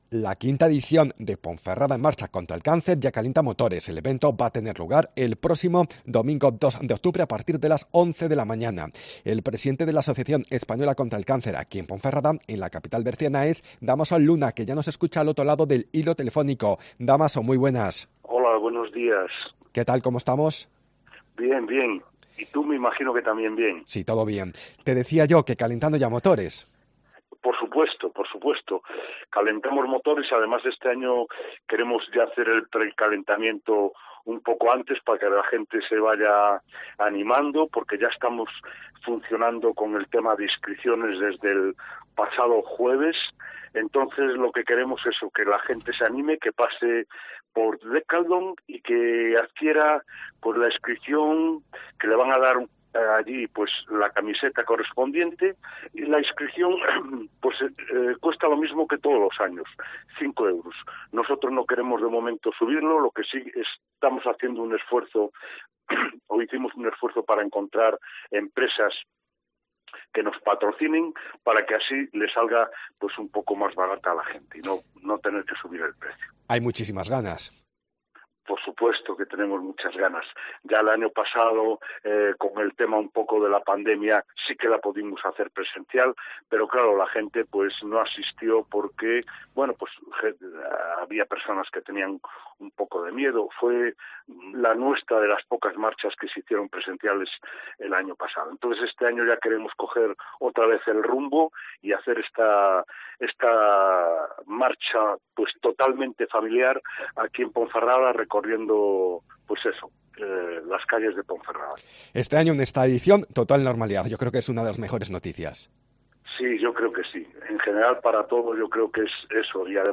SOCIEDAD-MEDIODÍA COPE